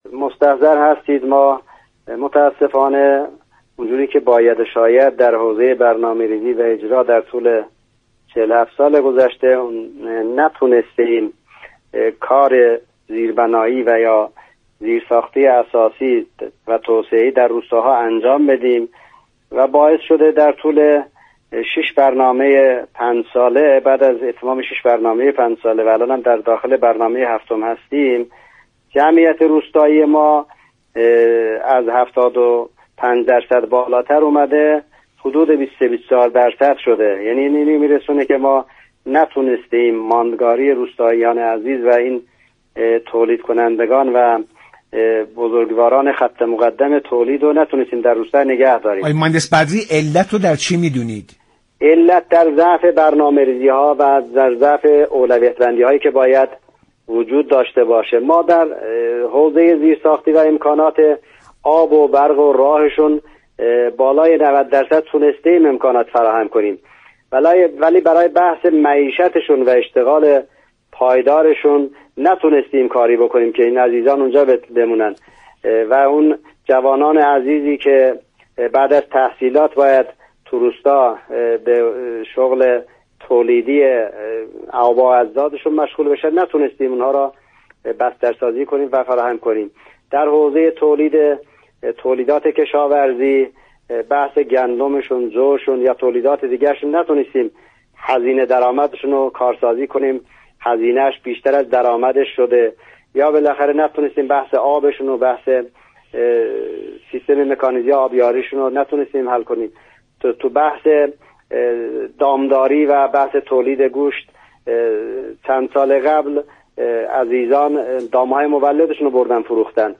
عضو فراكسیون روستایی مجلس در برنامه ایران امروز گفت: جمعیت روستایی ایران از 75درصد به 23درصد رسیده است.